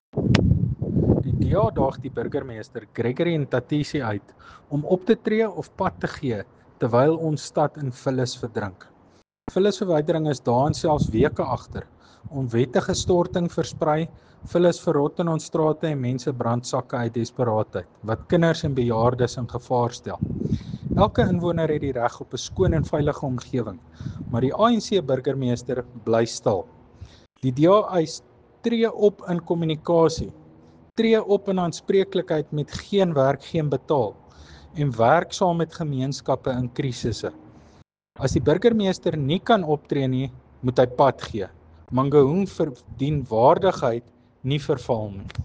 Afrikaans soundbites by Cllr Tjaart van der Walt and Sesotho soundbite by Cllr Kabelo Moreeng.